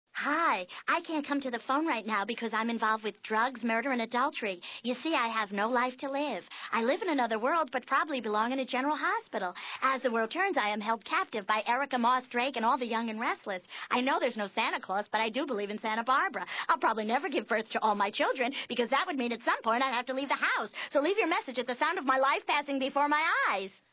At The Sound Of The Beep... Soap Suds
Because the cassette these recordings were archived from was not properly labeled.